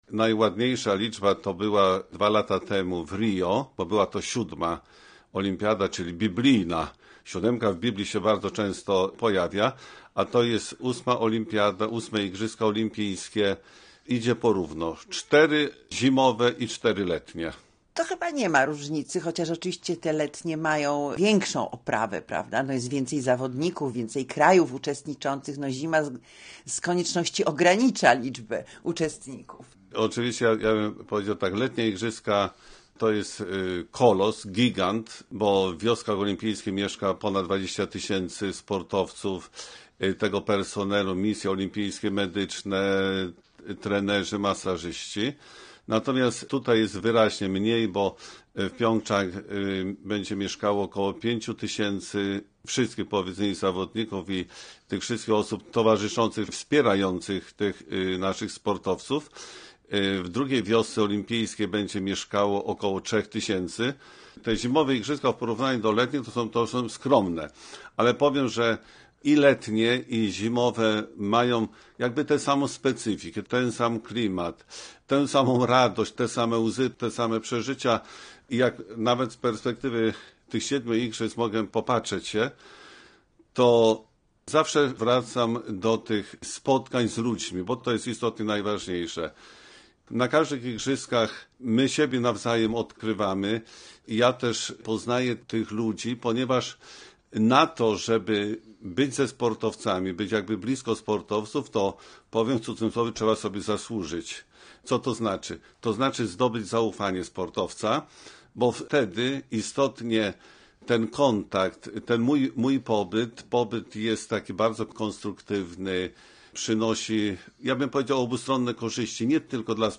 Rozmowa z duszpasterzem sportowców